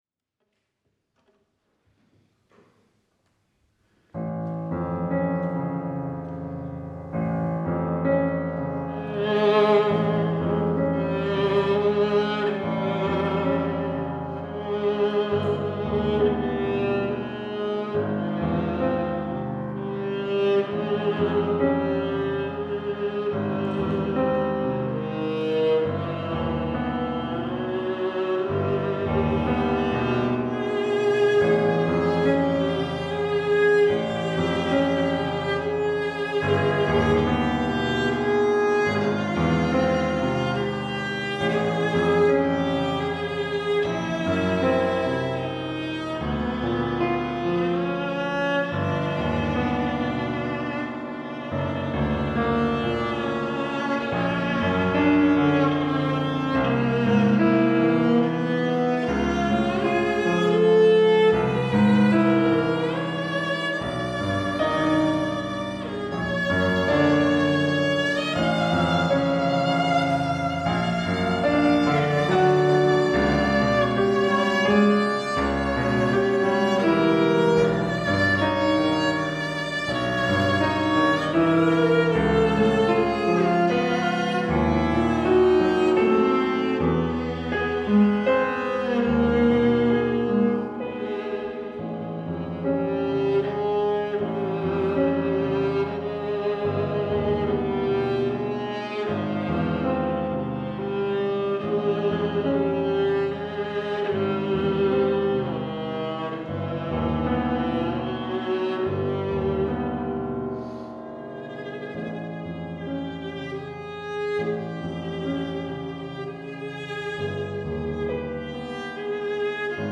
Viola
Piano
Jacqueline Du Pre Recital Hall Oxford